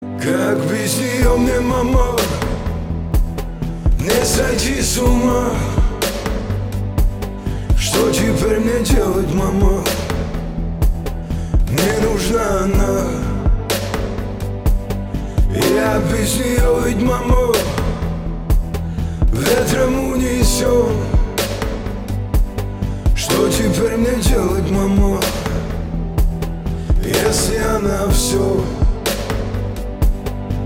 • Качество: 320, Stereo
лирика
красивый мужской голос
мелодичные